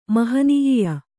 ♪ mahanīya